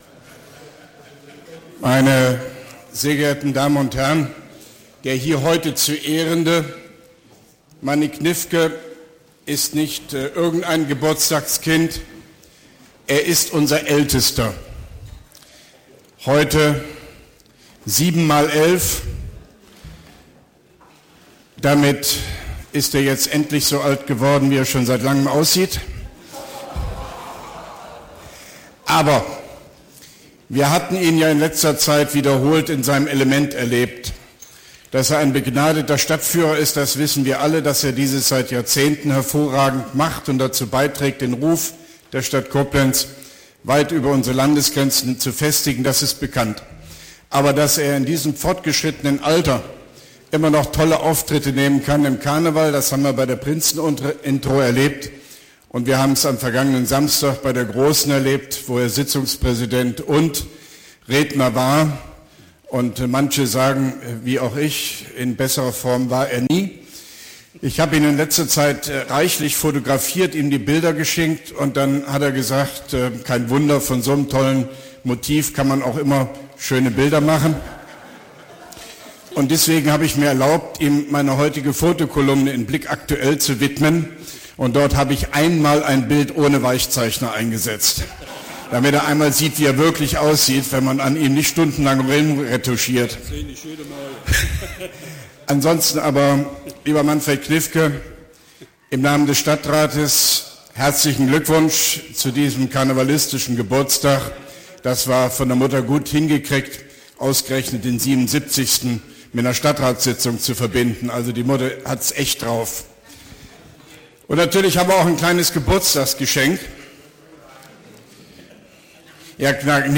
Symbol audio-home Blanko OB-Reden
Gratulation an Alterspräsidenten Ratsherrn Gniffke zum 77. Geburtstag: Rede von OB Hofmann-Göttig vor Eintritt in die Tagesordnung des Koblenzer Stadtrats, 28.01.2016